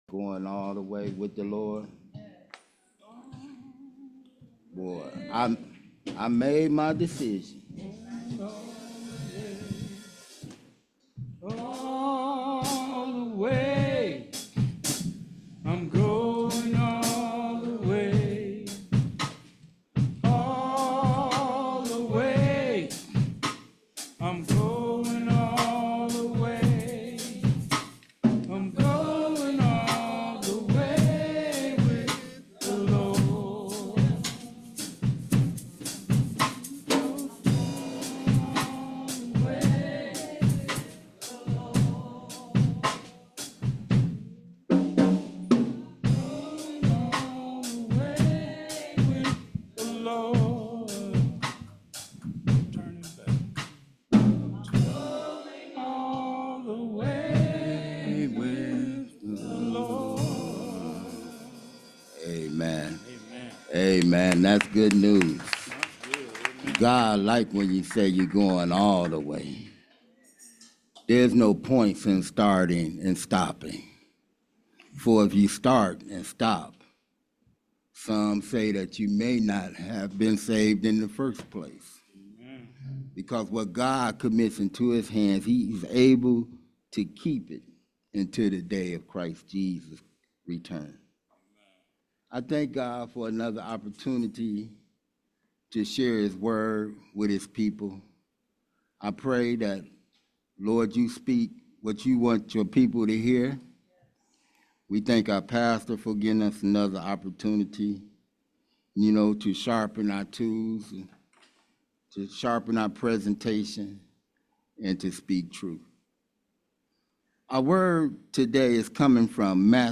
This sermon is based on Matthew 9:27-30, where Jesus heals two blind men.